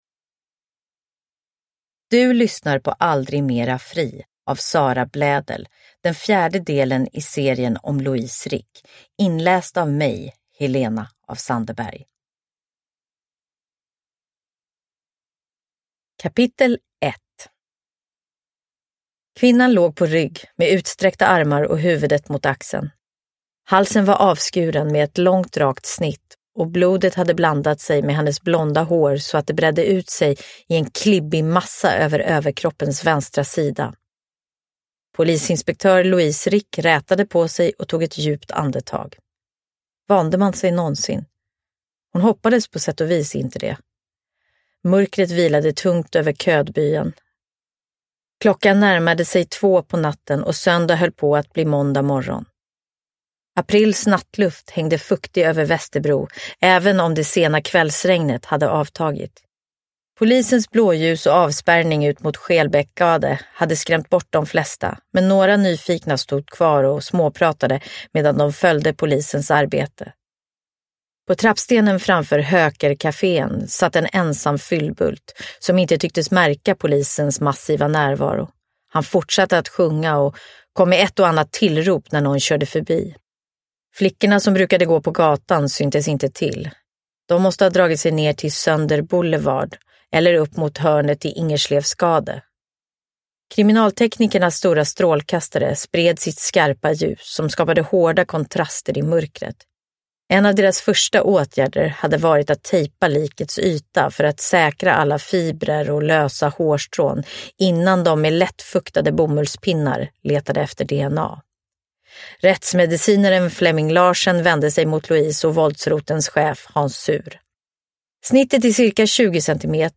Aldrig mera fri – Ljudbok
Uppläsare: Helena af Sandeberg